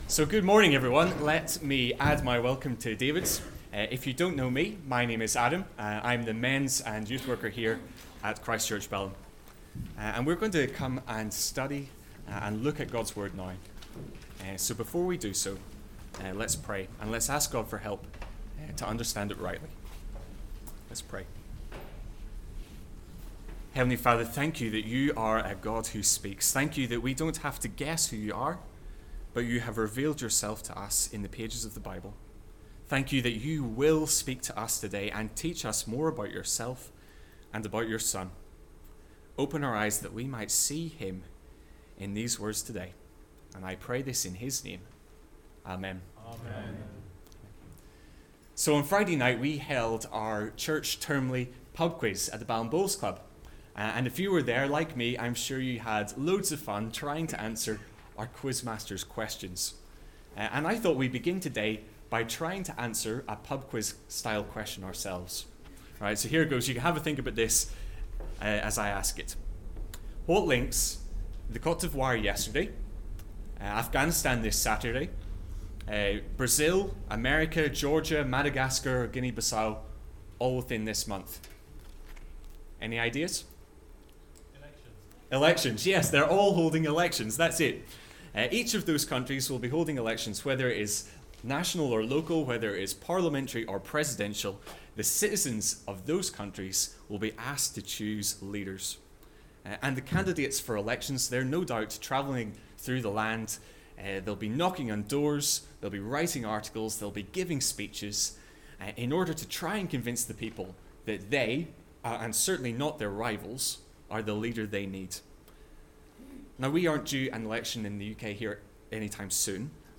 The first sermon on our series looking at the life of Gideon. We were looking at Judges 6:1-32 and the two points are: 1. Our need for leadership (1-10) 2. The leader we need (11-32) Preacher